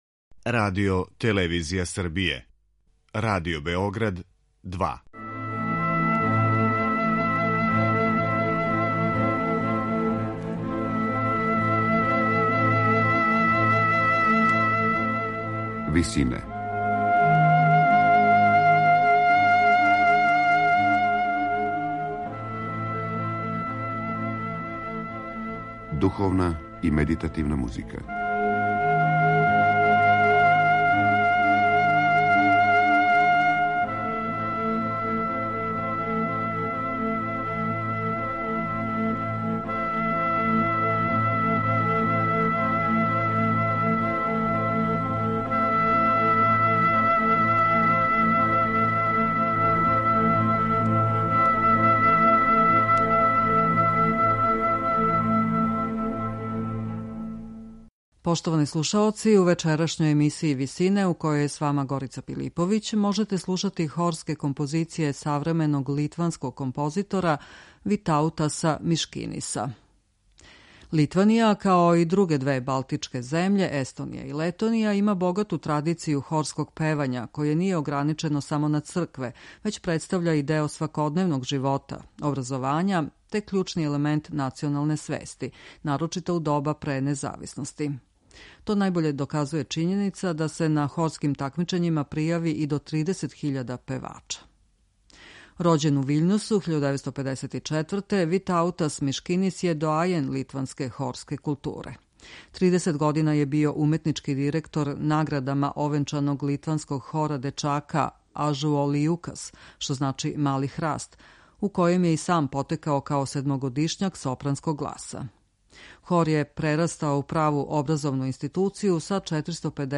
духовне и световне хорске композиције